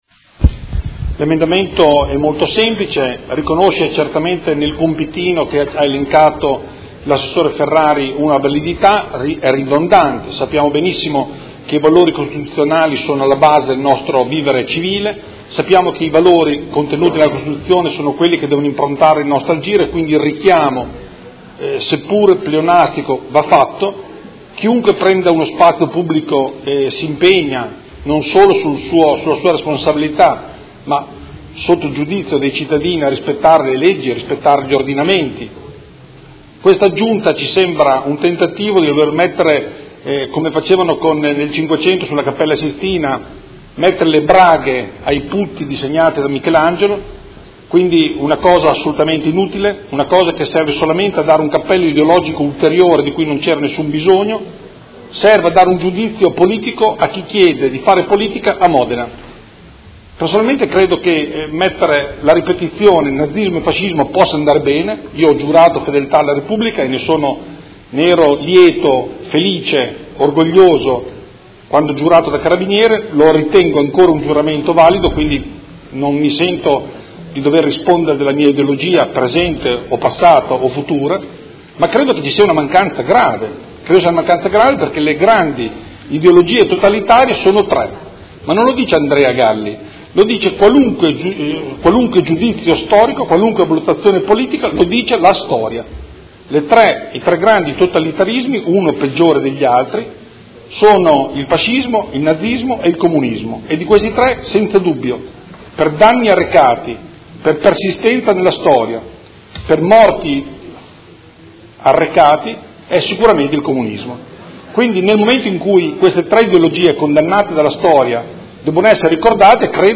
Seduta del 24/01/2019. Emendamento Prot. Gen. n. 20225 presentato dai Consiglieri Galli e Morandi (Forza Italia)